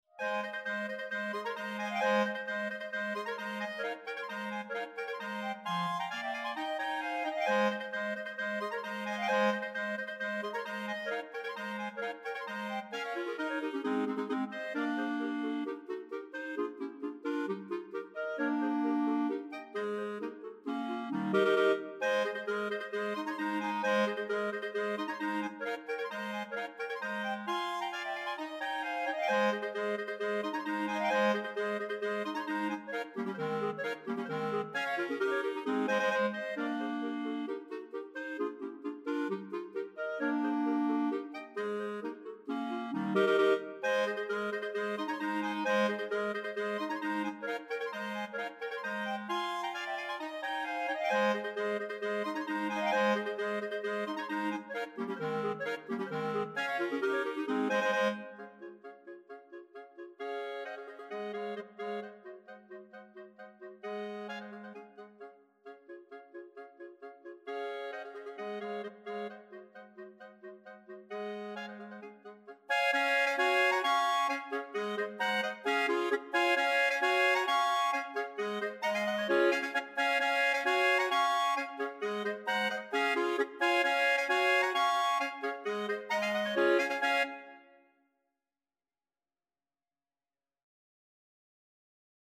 Voicing: Mixed Clarinet Quartet